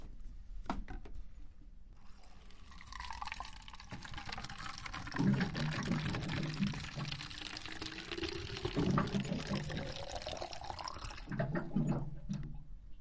水声》第一卷 " 用饮水机装水瓶
描述：用水冷却器中的水填充我的瓶子。 用Zoom H1记录。
Tag: 冷水器 饮料 冷却器 倾倒 拟音